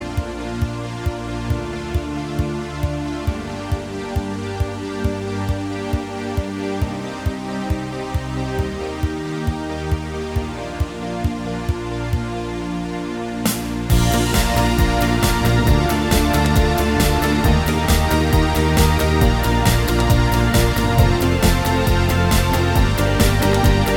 No Guitars Pop